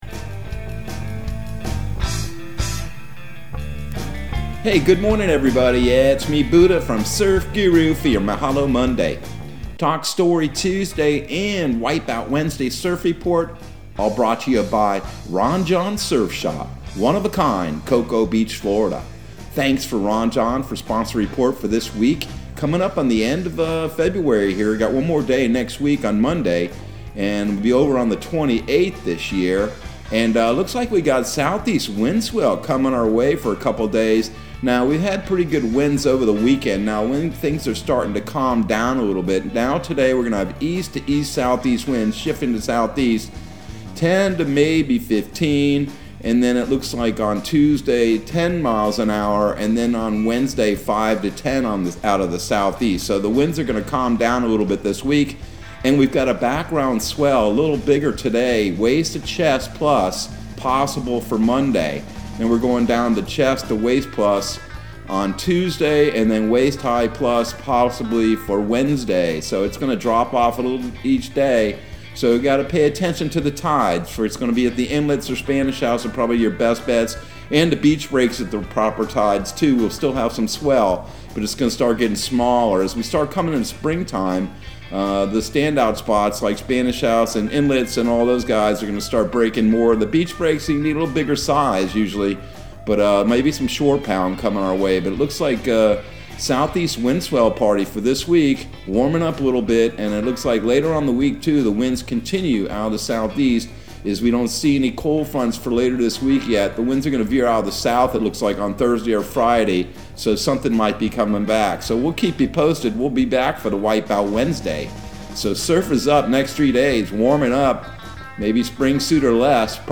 Surf Guru Surf Report and Forecast 02/21/2022 Audio surf report and surf forecast on February 21 for Central Florida and the Southeast.